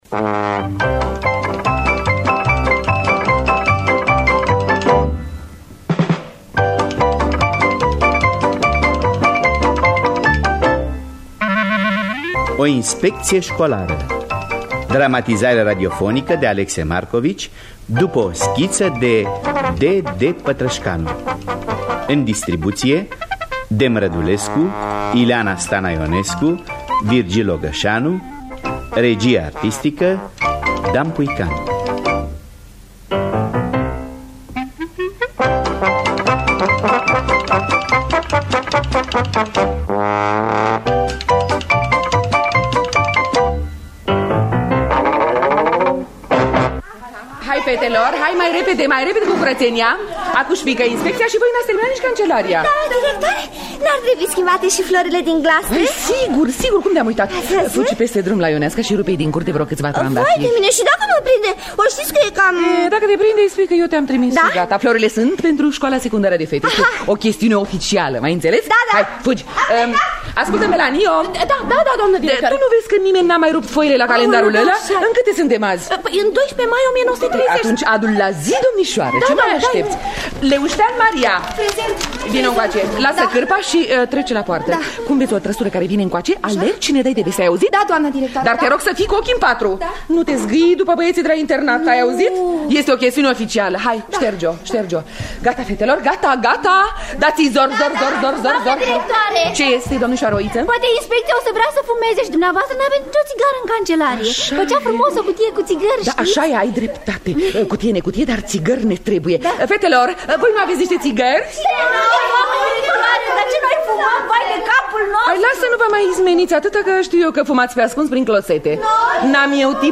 Dramatizarea radiofonică de Alexe Marcovici.